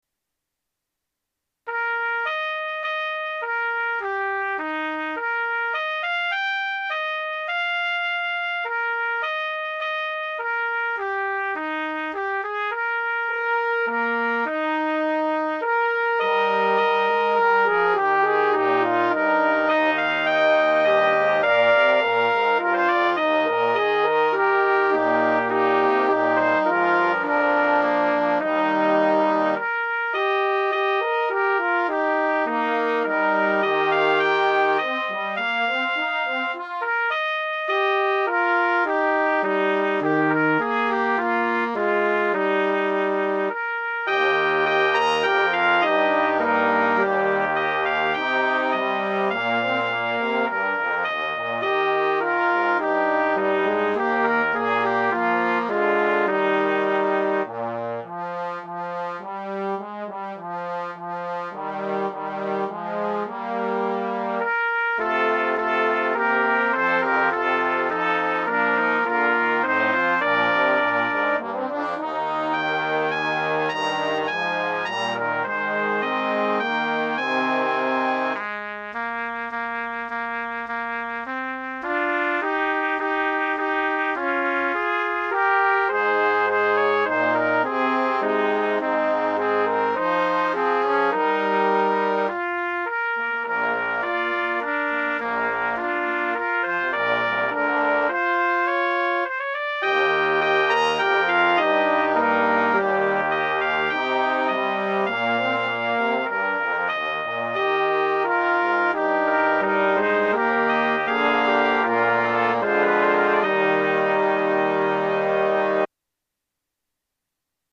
Midi-rendered Dry version First reading.